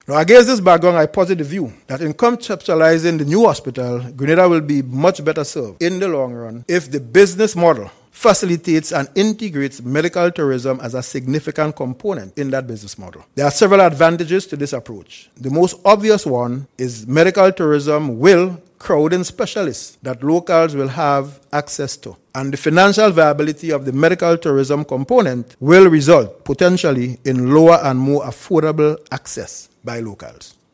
Proposed Medical Tourism niche in Grenada discussed during ECCB’s Anniversary Lecture Series